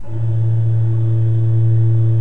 Index of /90_sSampleCDs/Propeller Island - Cathedral Organ/Partition K/KOPPELFLUT R